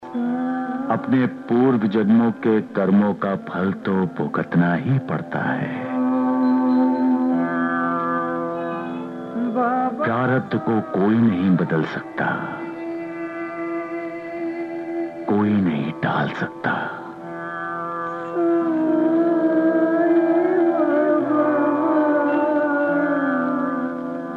Some dialogues from the Ramanand Sagar's SAIBABA,